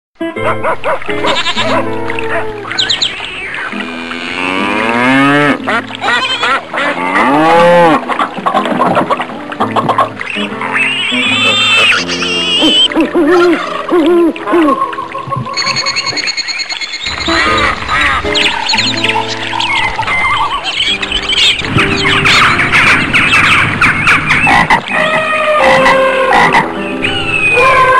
Alle Tiere klingen klingelton kostenlos
Kategorien: Tierstimmen